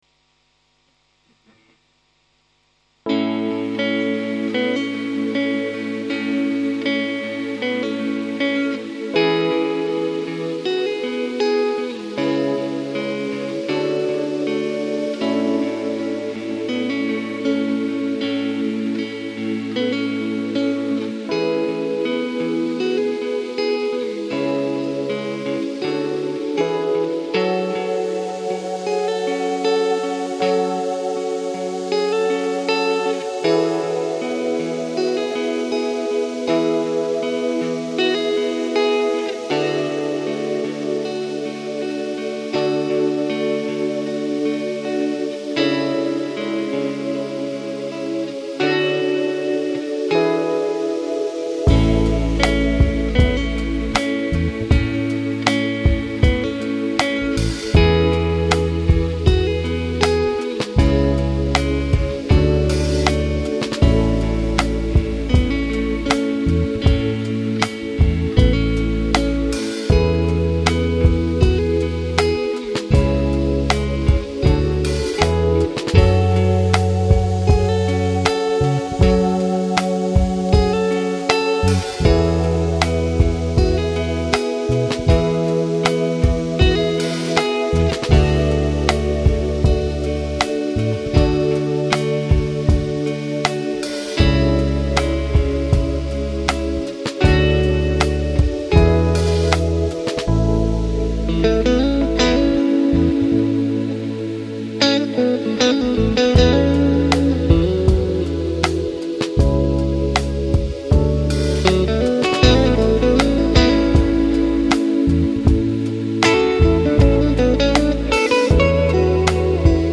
Жанр: Chill Out, Smooth Jazz, Downtempo